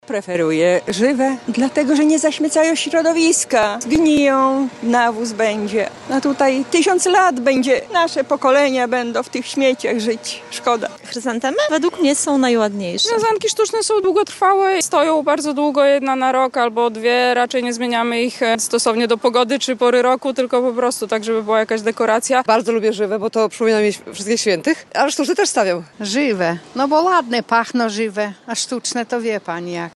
Zapytaliśmy odwiedzających nekropolię na Majdanku, jak wyglądają ich wybory zakupowe w tym roku:
SONDA sztuczne czy żywe Mixdown 1